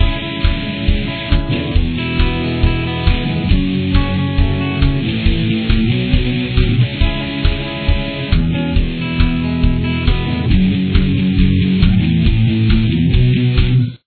Bridge